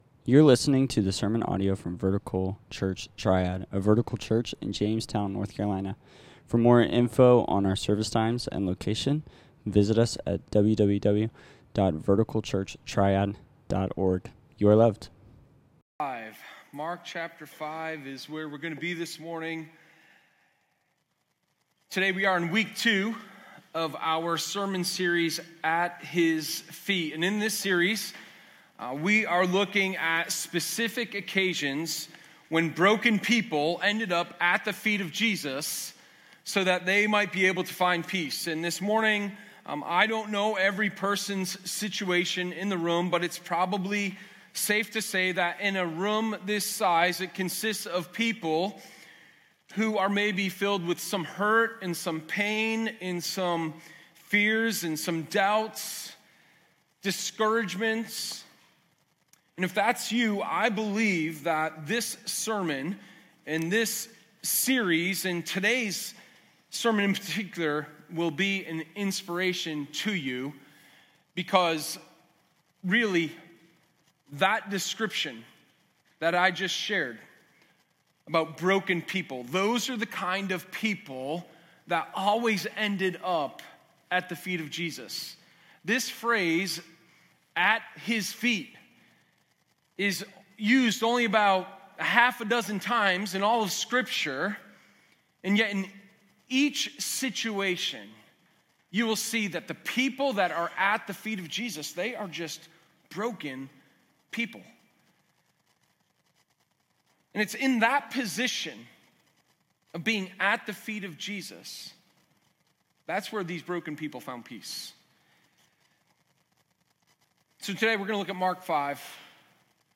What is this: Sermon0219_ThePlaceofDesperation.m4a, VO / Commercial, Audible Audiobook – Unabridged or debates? Sermon0219_ThePlaceofDesperation.m4a